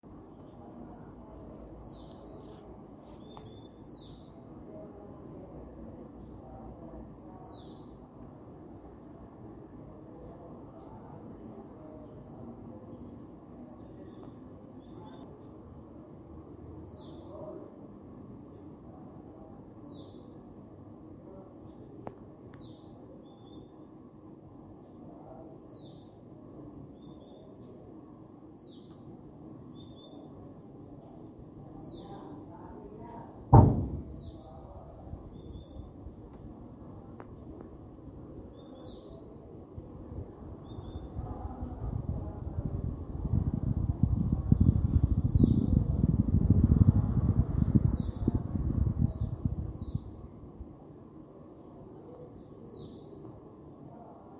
Tangará Común (Euphonia chlorotica)
Clase: Aves
País: Argentina
Condición: Silvestre
Certeza: Vocalización Grabada
TANGARA-COMUN.mp3